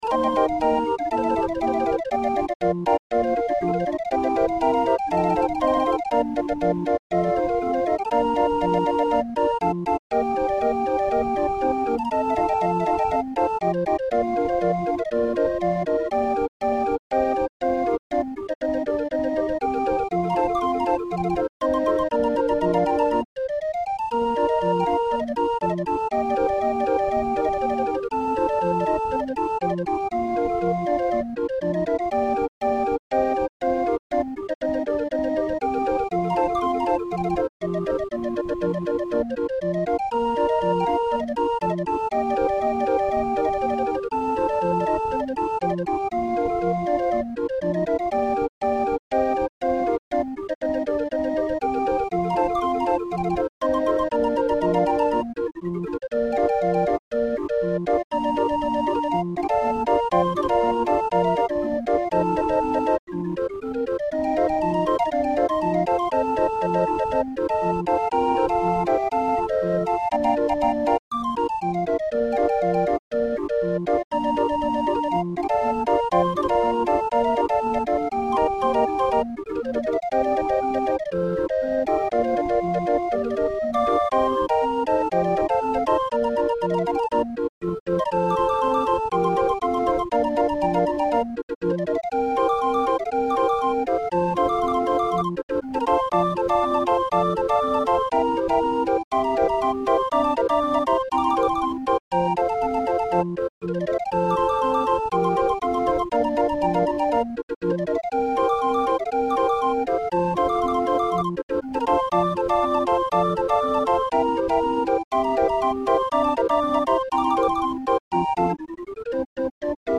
Musikrolle 20-er Raffin